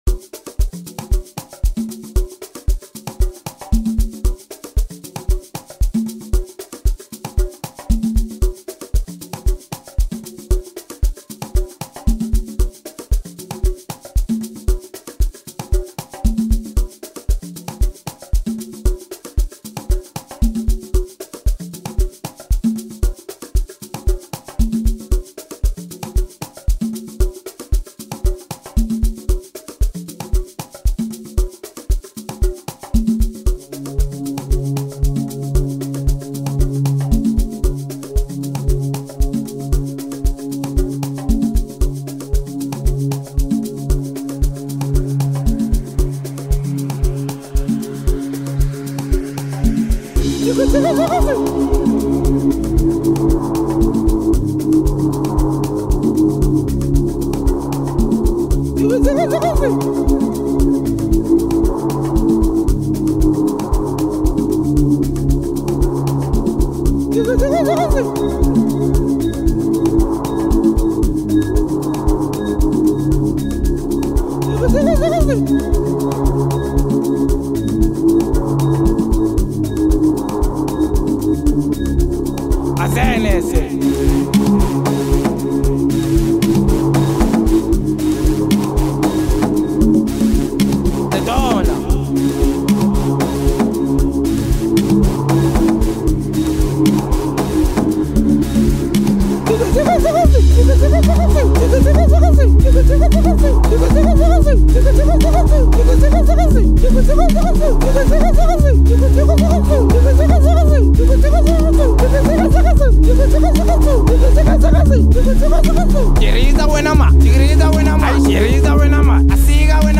piano music
The intricate basslines and flawless blending of PSP vibes
Highlighting his different paths in the Amapiano music scene